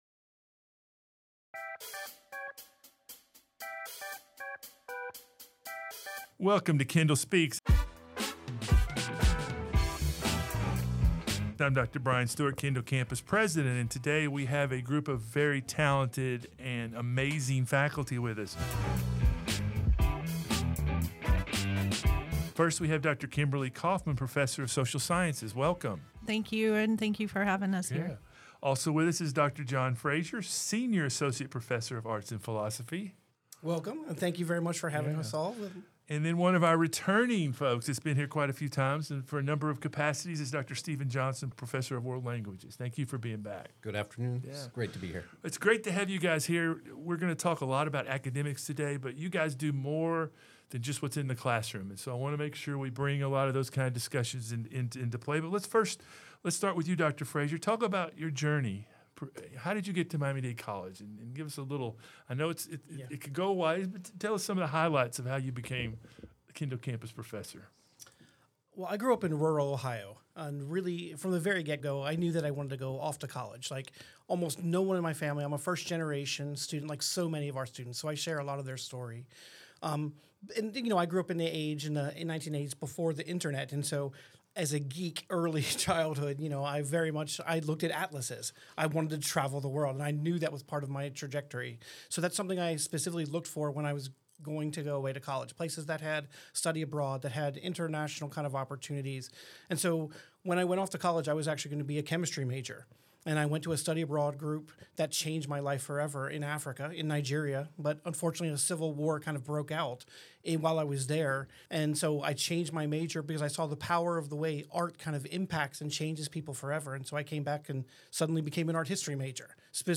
Miami Dade College's faculty is awesome. To prove it, we have three amazing faculty members joining us for this very special episode of Kendall Speaks.